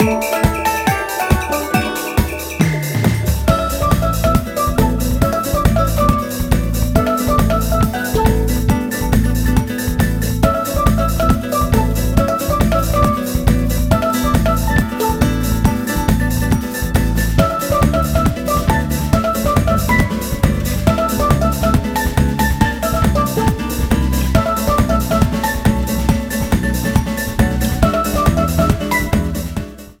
Shallow Water Frontrunning music